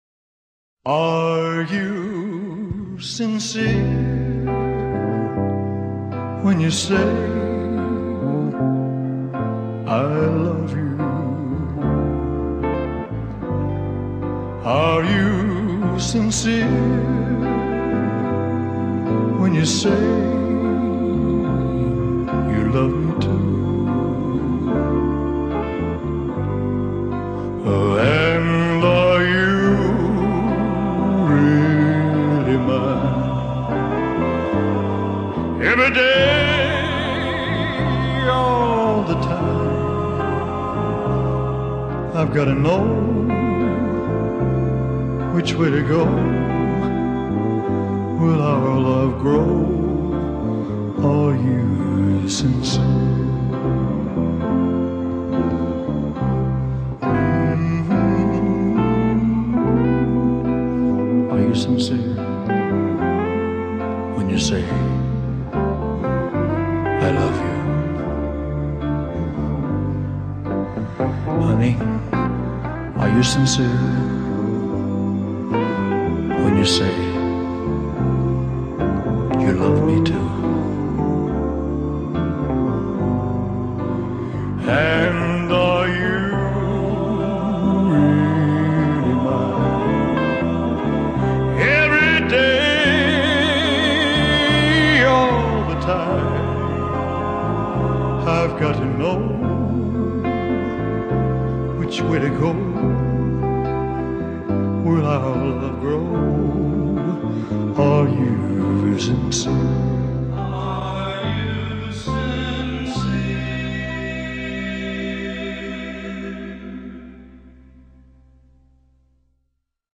Following are the professional, recorded versions.